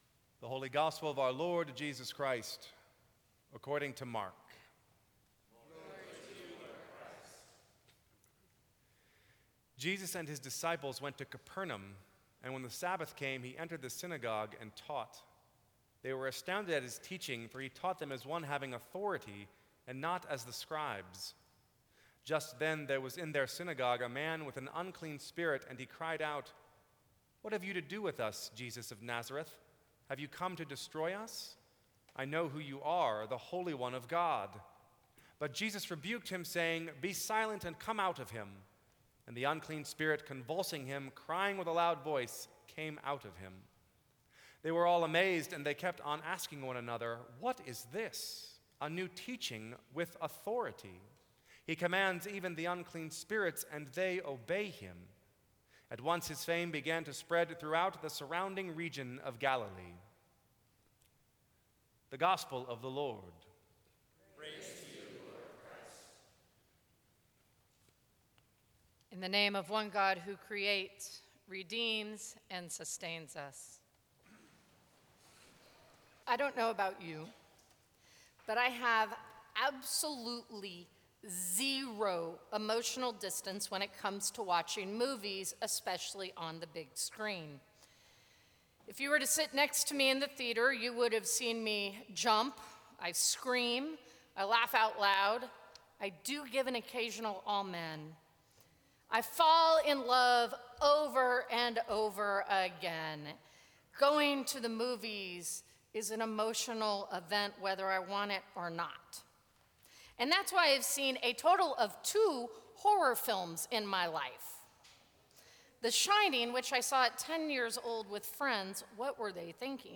Sermons from St. Cross Episcopal Church Asking for what you need Sep 25 2015 | 00:15:09 1x 00:00 / 00:15:09 Subscribe Share Apple Podcasts Spotify Overcast RSS Feed Share Link Embed